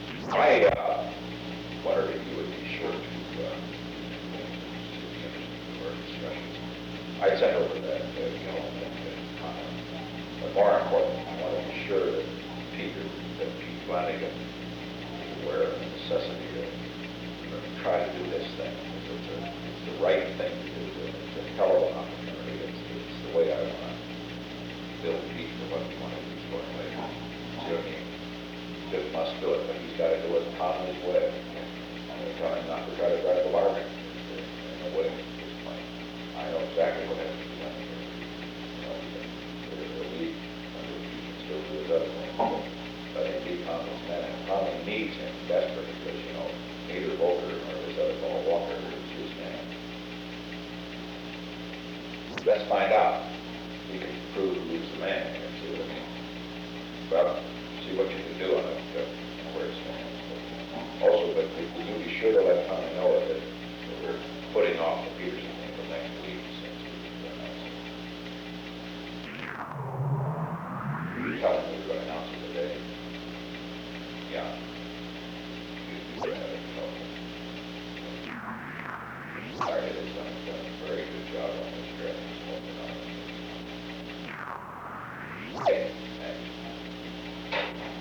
The Old Executive Office Building taping system captured this recording, which is known as Conversation 314-011 of the White House Tapes. Nixon Library Finding Aid: Conversation No. 314-11 Date: January 18, 1972 Time: Unknown between 3:45 pm and 4:12 pm Location: Executive Office Building The President talked with H. R. (“Bob”) Haldeman.